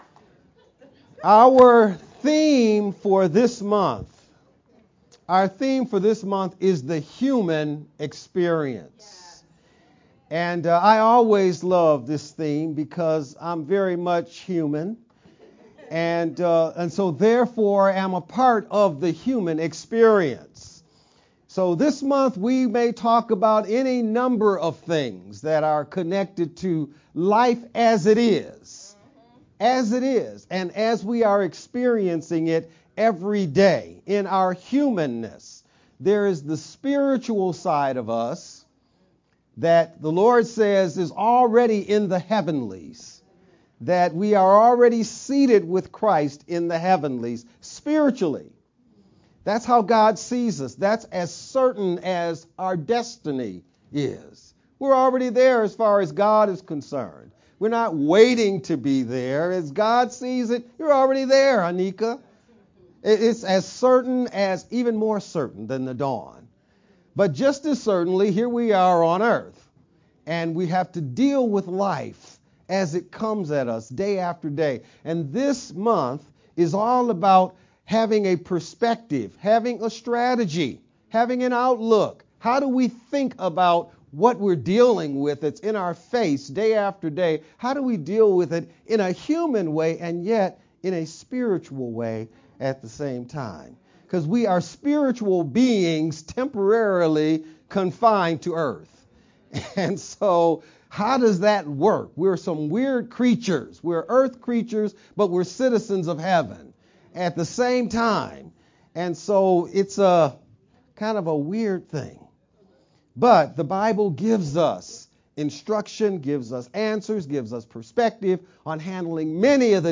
VBCC-Sermon-edited-Oct.1st-sermon-only_Converted-CD.mp3